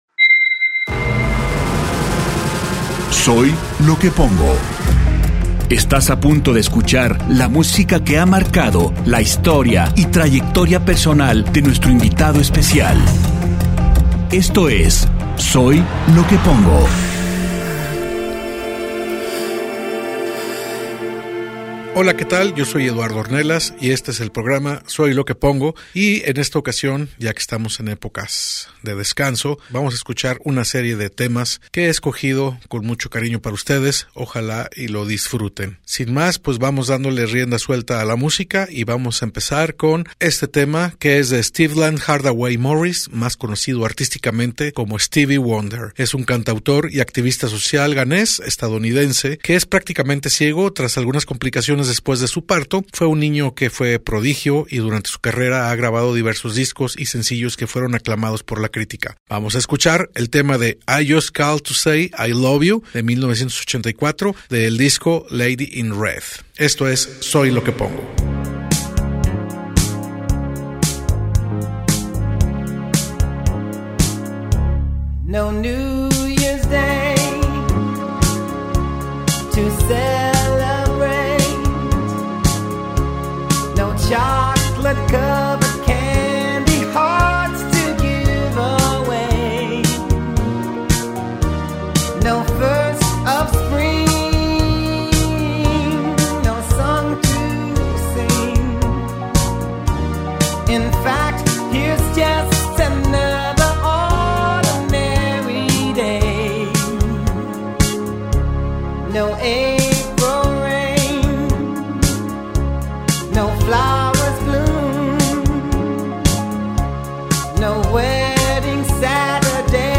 Baladas Death N Love